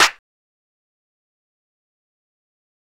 REDD CLap.wav